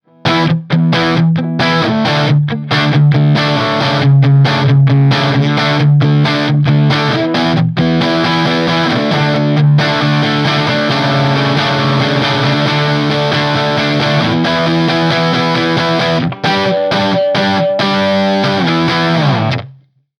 TC-15 DIRTY
Orange V30
The TC-15 tone was the Ch.1 EF86, "Lo" input, "munch".
TC15_DIRTY_OrangeV30.mp3